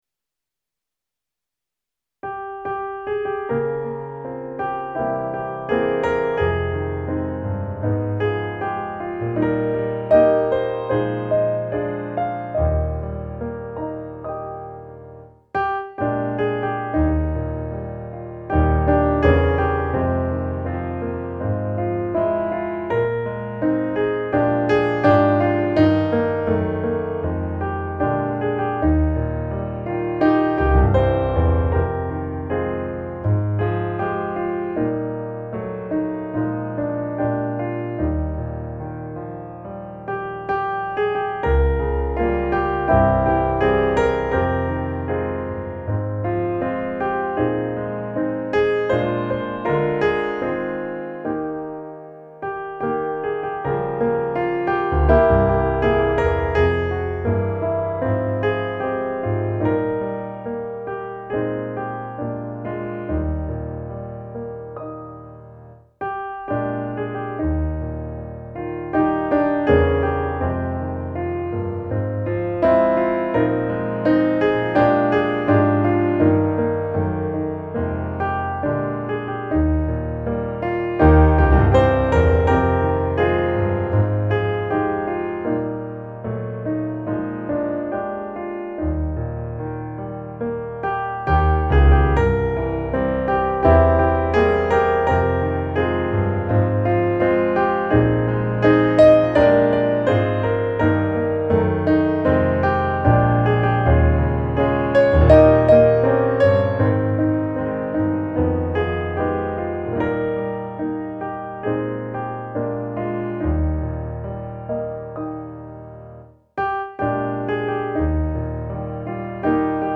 Gemensam sång
Musikbakgrund Psalm